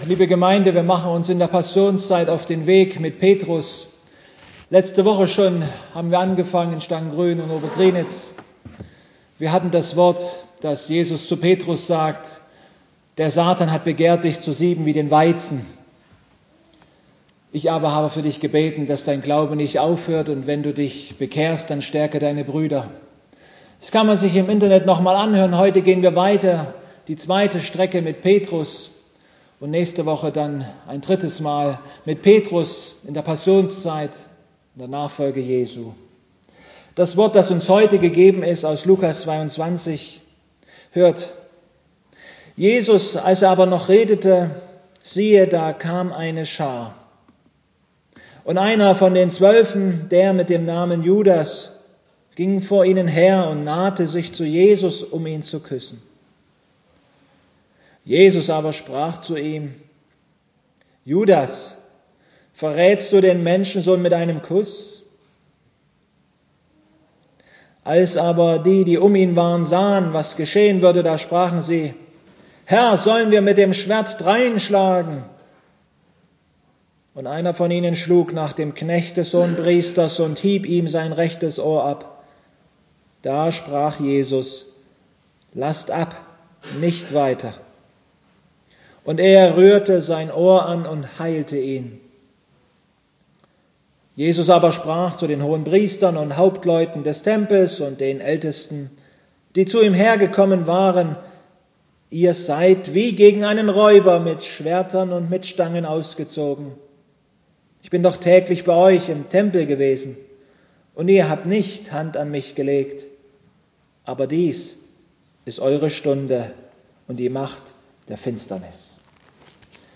Gottesdienstart: Predigtgottesdienst